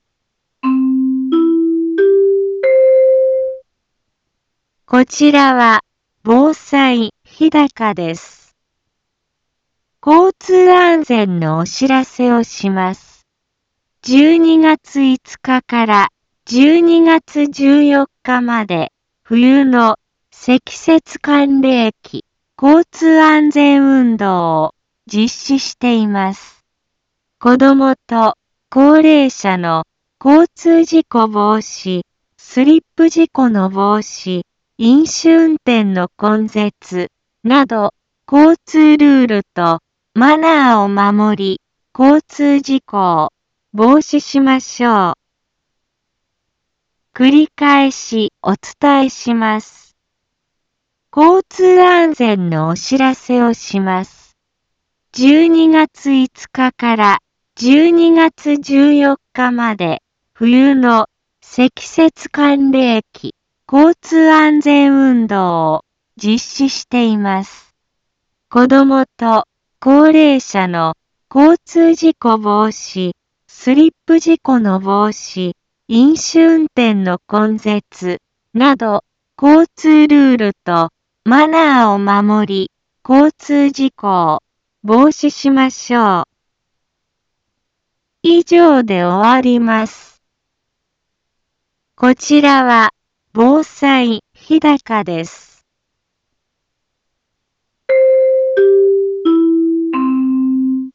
一般放送情報
Back Home 一般放送情報 音声放送 再生 一般放送情報 登録日時：2019-12-05 10:03:33 タイトル：交通安全のお知らせ インフォメーション：こちらは、防災日高です。